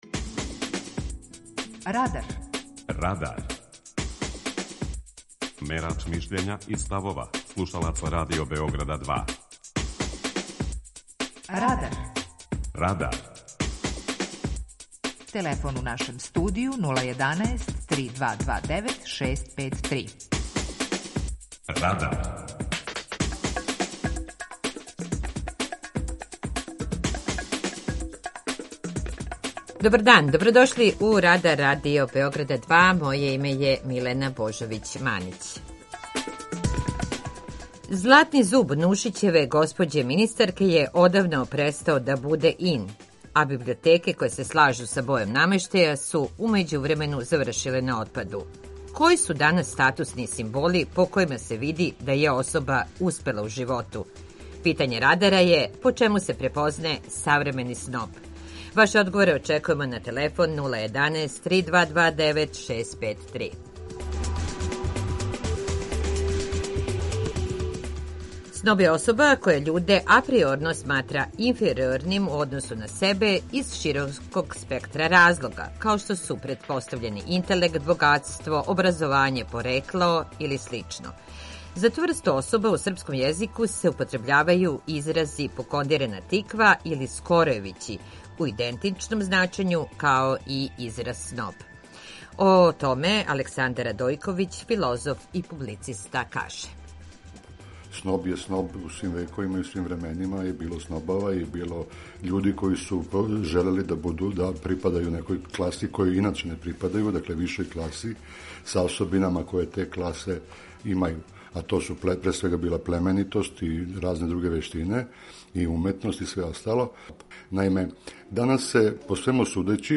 Питање Радара: По чему се препознаје савремени сноб? преузми : 19.02 MB Радар Autor: Група аутора У емисији „Радар", гости и слушаоци разговарају о актуелним темама из друштвеног и културног живота.